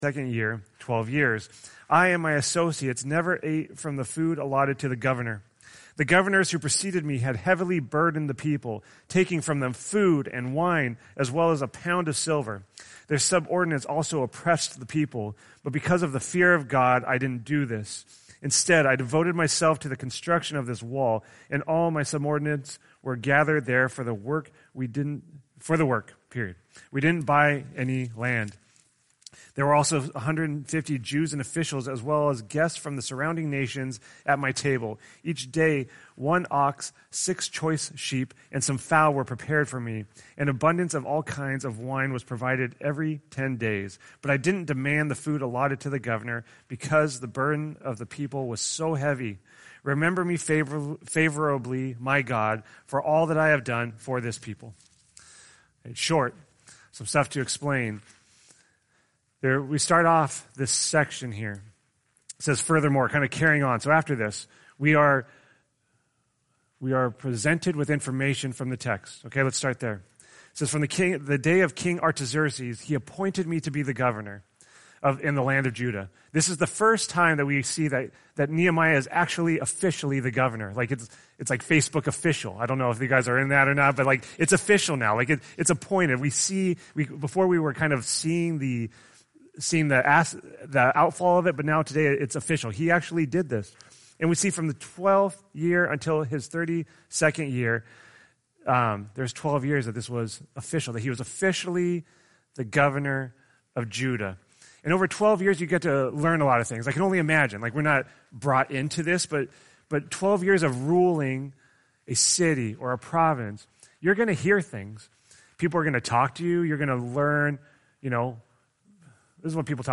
We had some technical issues, so the beginning of the sermon was cut off.